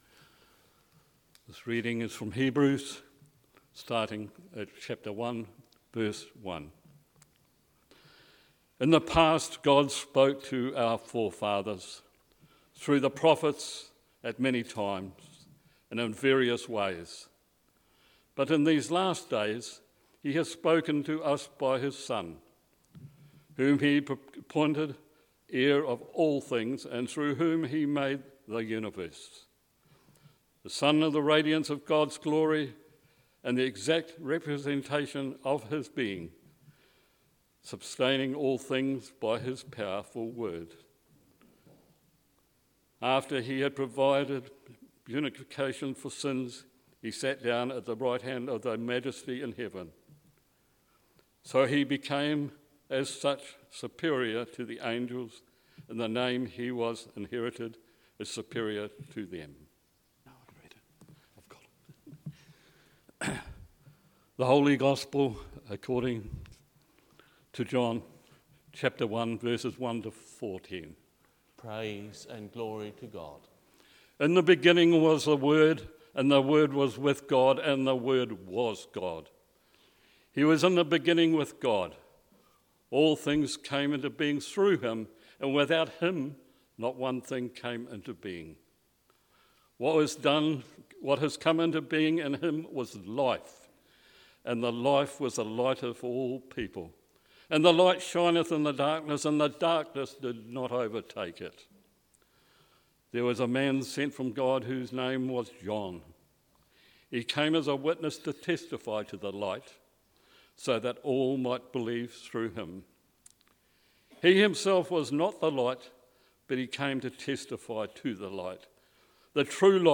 A sermon on Christmas Day